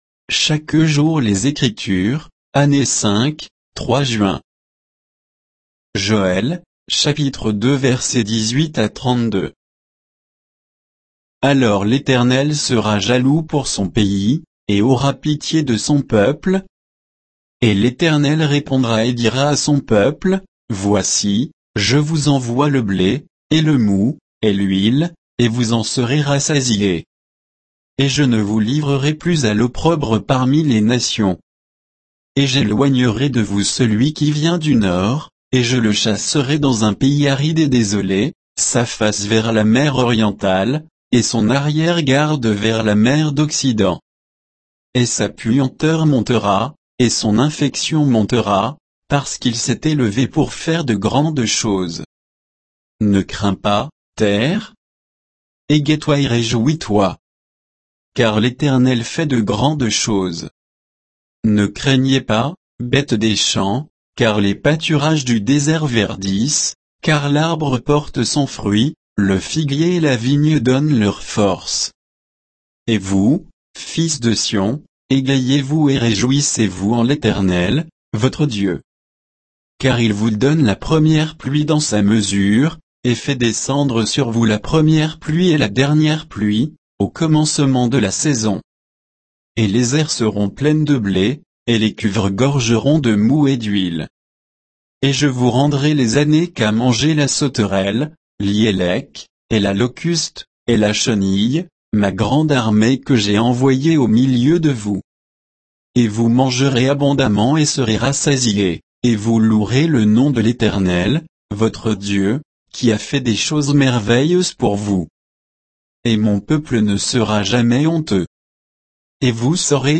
Méditation quoditienne de Chaque jour les Écritures sur Joël 2, 18 à 32